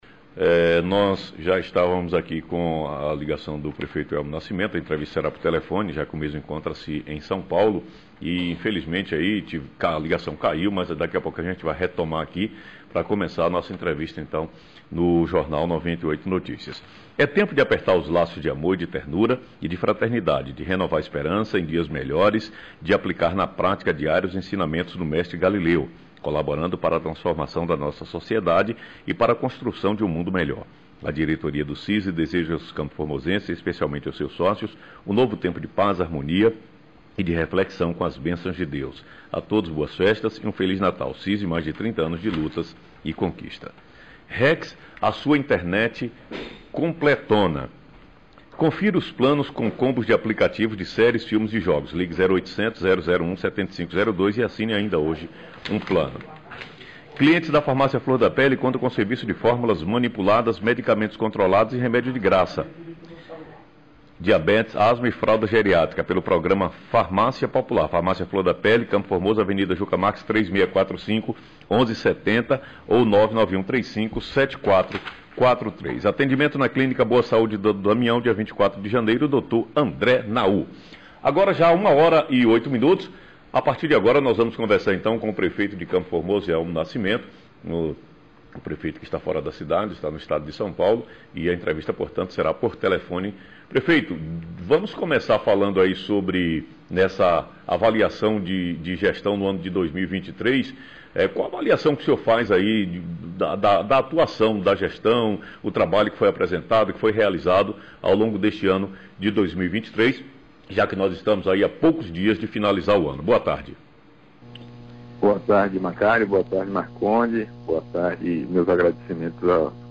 Entrevista por telefone com o prefeito Elmo e o Deputado Federal Elmar Nascimento